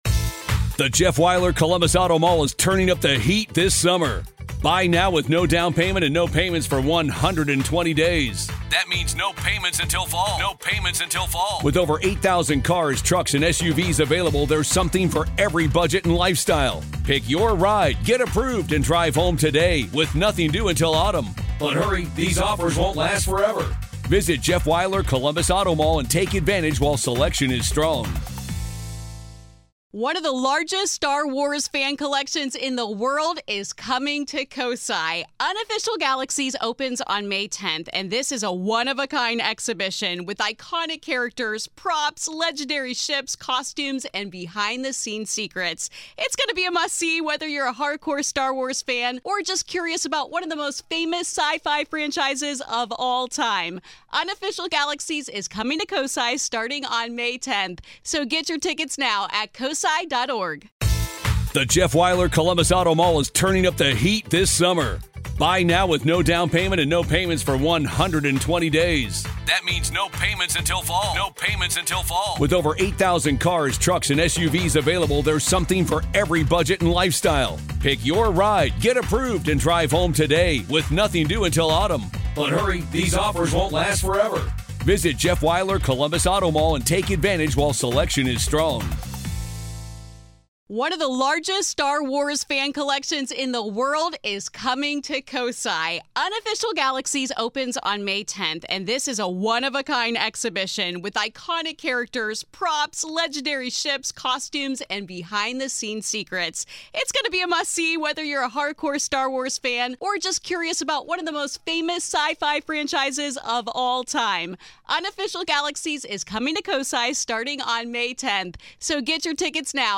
In this episode, we explore the overlap between mental health and paranormal claims, ask how often the dead are tied to criminal investigations, and dig into the strange and tragic cases where the lines between life, death, and justice blur. Strap in for a conversation that’s part detective drama, part ghost story